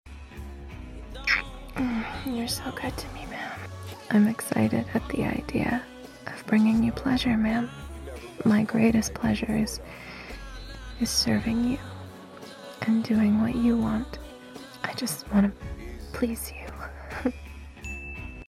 🎧😎The Deep Voice Makes It Sound Effects Free Download